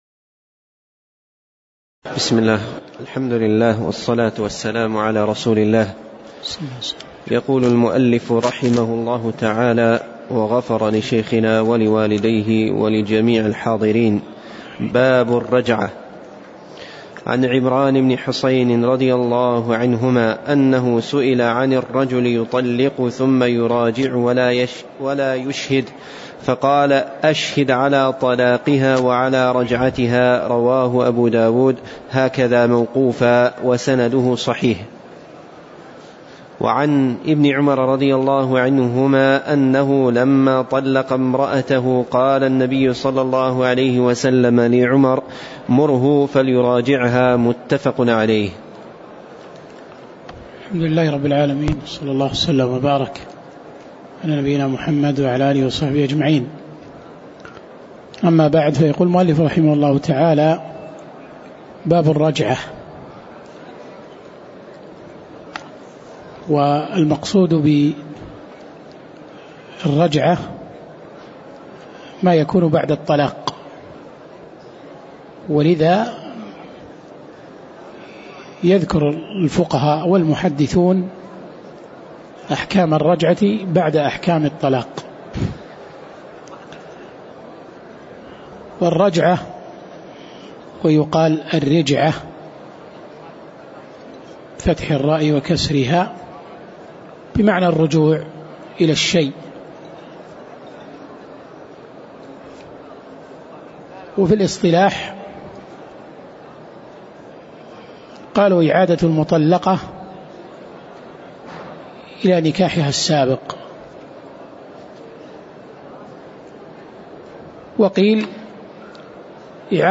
تاريخ النشر ١٥ جمادى الآخرة ١٤٣٨ هـ المكان: المسجد النبوي الشيخ